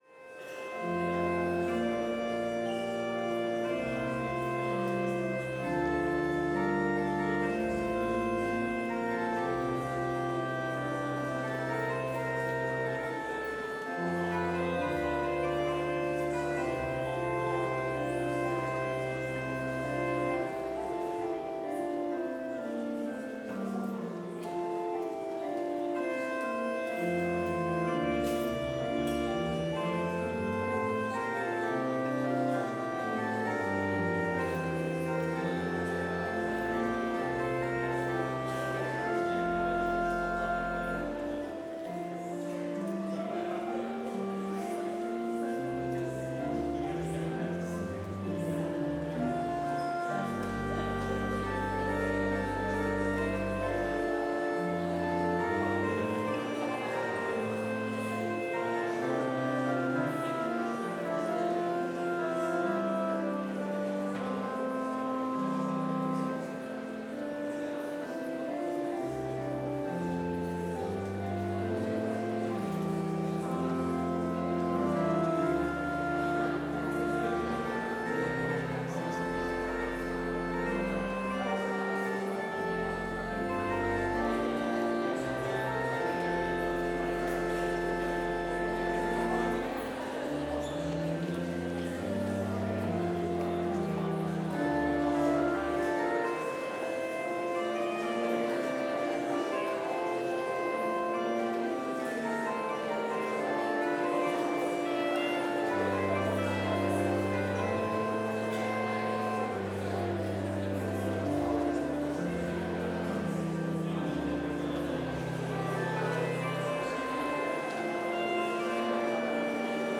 Chapel service held on August 25, 2025, in Trinity Chapel (video and audio available) reading Prelude reading Psalm 55
Complete service audio for Chapel - Monday, August 25, 2025